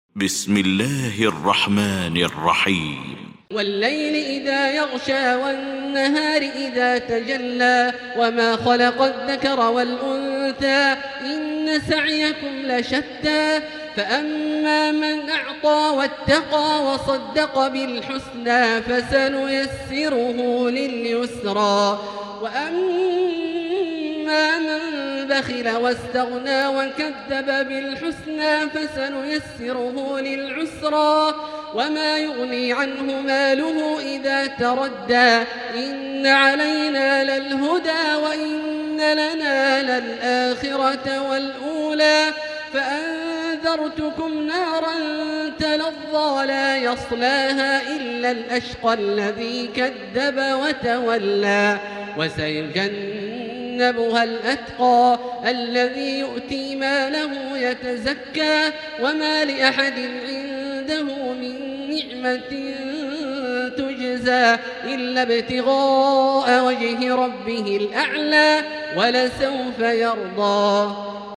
المكان: المسجد الحرام الشيخ: فضيلة الشيخ عبدالله الجهني فضيلة الشيخ عبدالله الجهني الليل